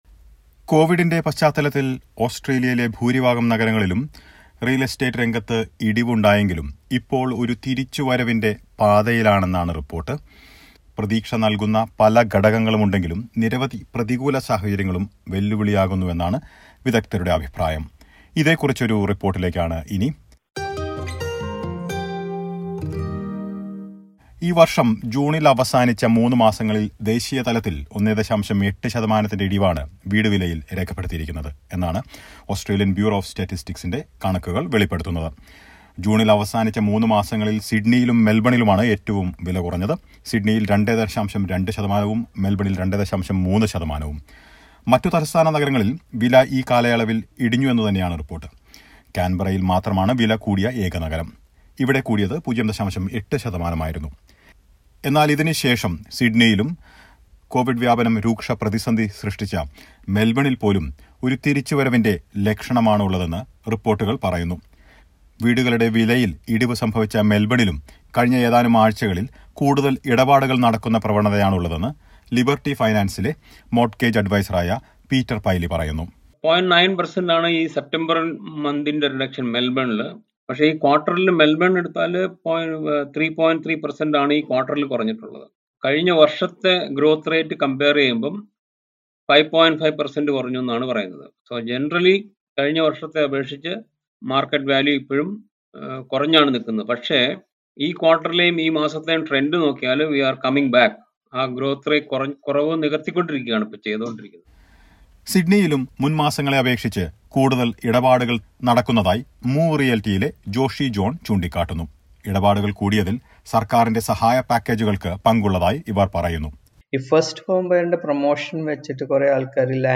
Listen to a report about the real estate trends in Australia.